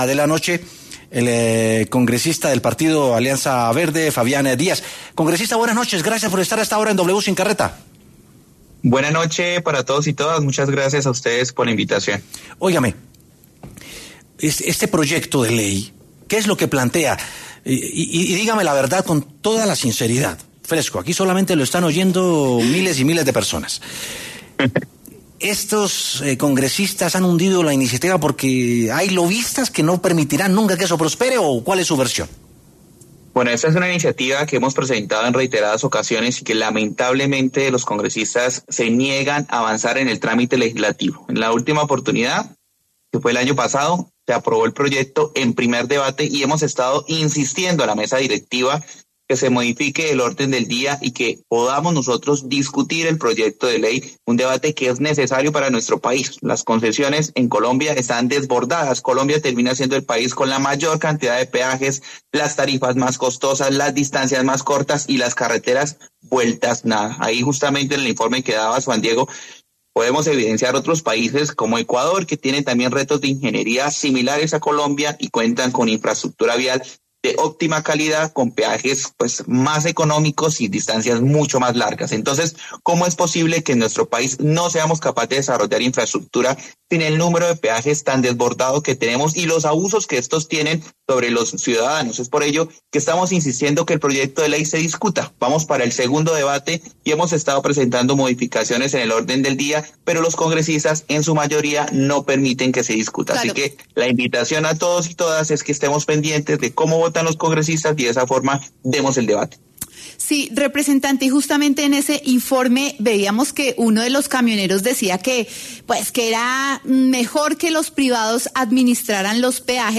El congresista de la Alianza Verde, Fabián Díaz, habló en W Sin Carreta para dar su perspectiva sobre la coyuntura, con el punto de que ha presentado 5 veces una iniciativa para regular los precios en los peajes, pero esta no ha avanzado.